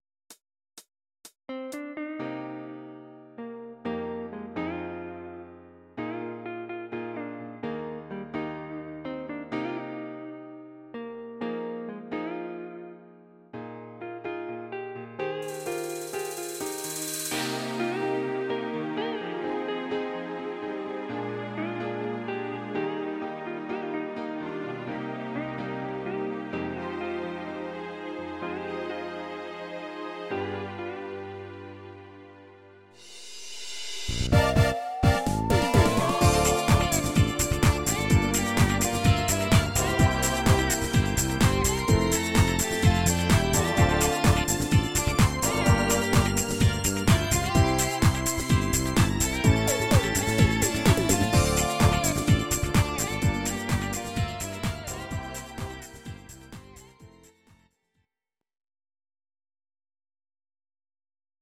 Please note: no vocals and no karaoke included.
Your-Mix: Disco (724)